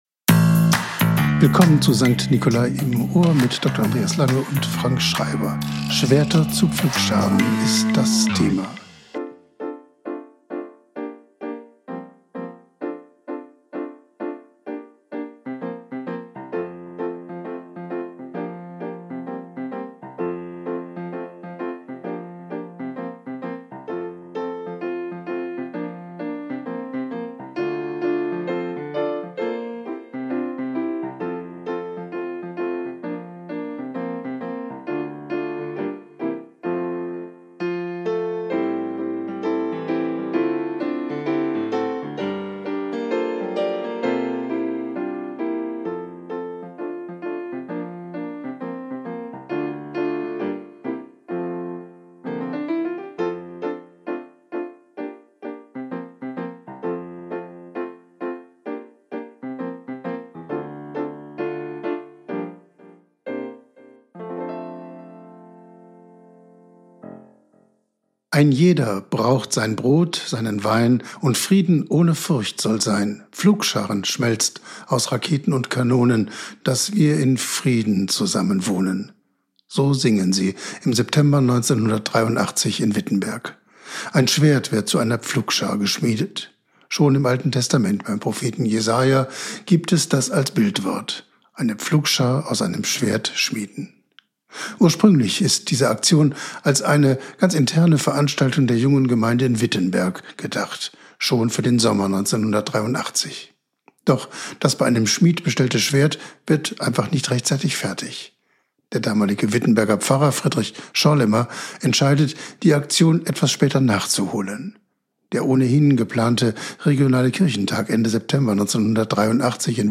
Mezzosopran
Flöte
Klarinette
Violine
Viola
Orgel, Klavier und Leitung